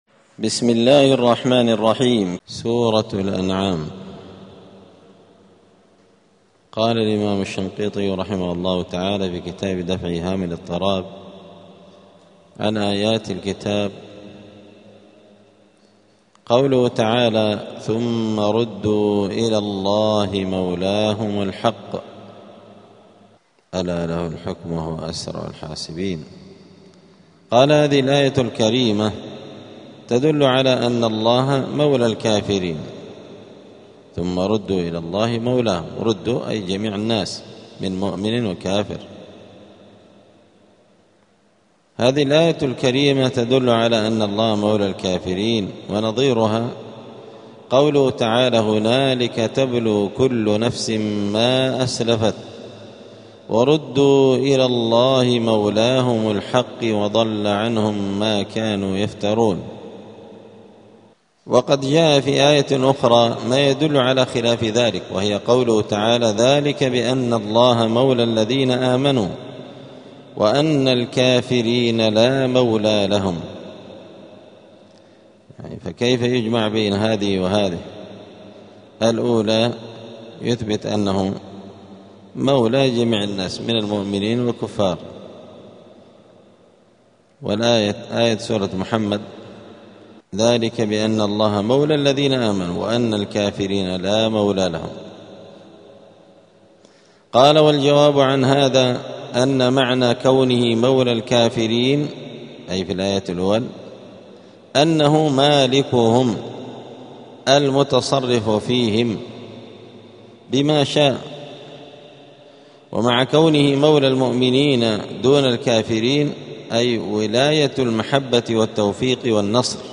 الجمعة 8 شوال 1447 هــــ | الدروس، دروس القران وعلومة، دفع إيهام الاضطراب عن آيات الكتاب | شارك بتعليقك | 3 المشاهدات